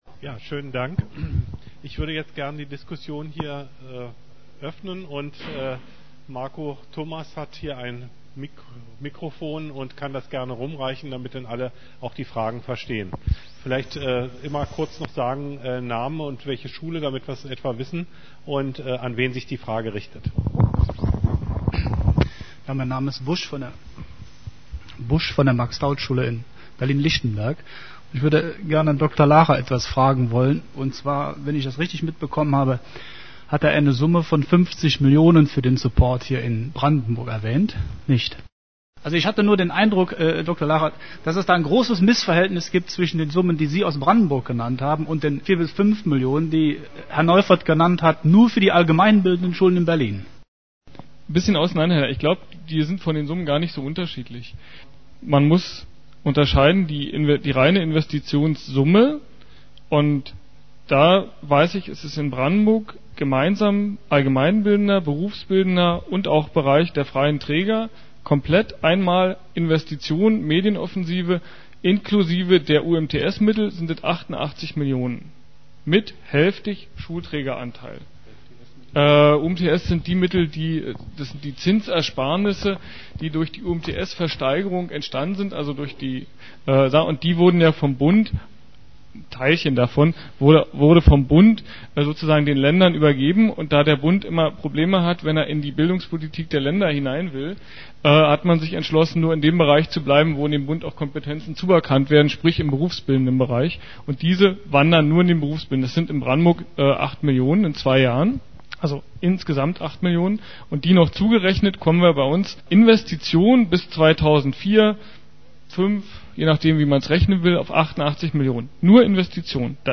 diskussion-frage1.mp3